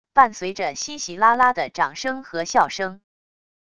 伴随着稀稀拉拉的掌声和笑声wav音频